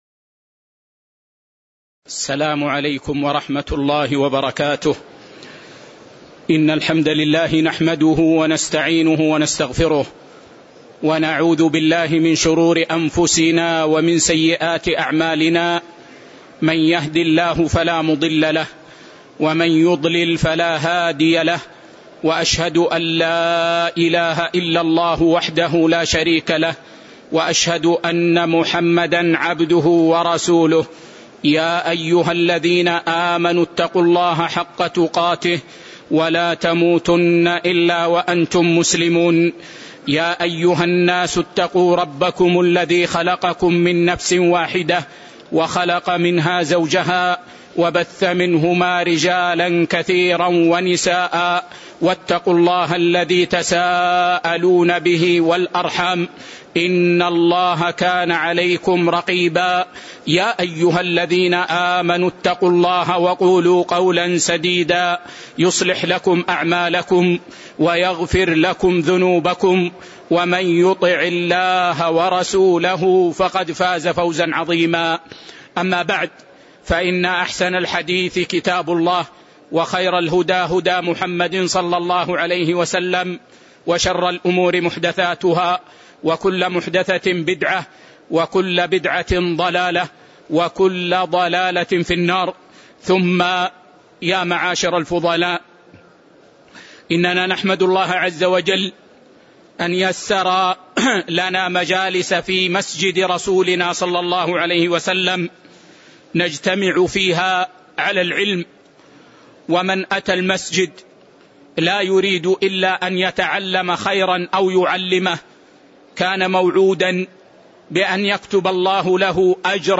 تاريخ النشر ٢٦ جمادى الأولى ١٤٤٠ هـ المكان: المسجد النبوي الشيخ